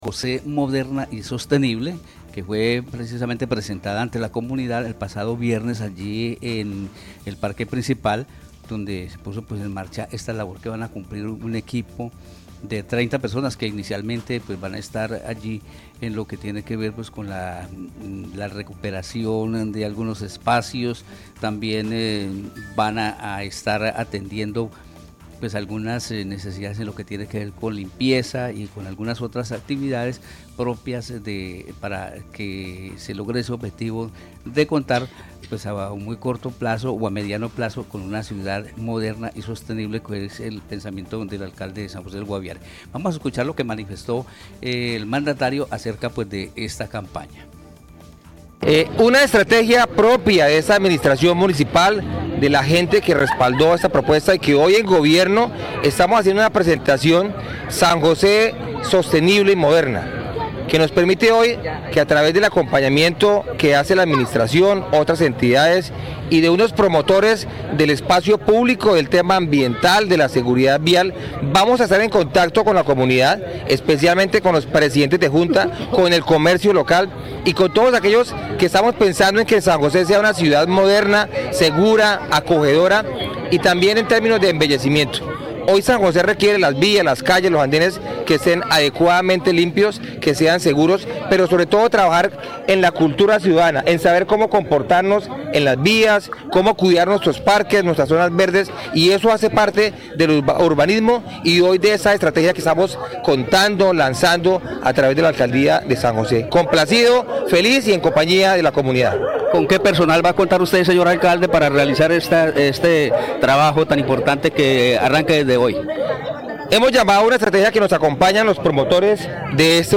El alcalde Willy Rodríguez, en entrevista con Marandua Noticias, explicó que la iniciativa se desarrollará mediante talleres educativos y acciones prácticas para embellecer los espacios públicos, promover el reciclaje y reducir el uso de plásticos en la ciudad.